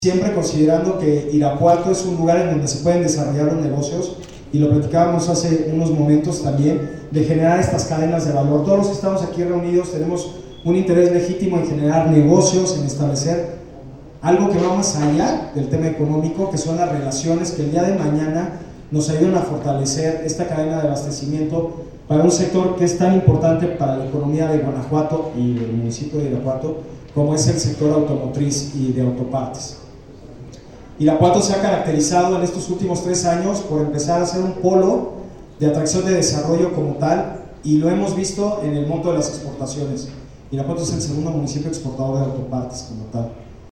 Héctor Muñoz Krieger, director de Economía y Turismo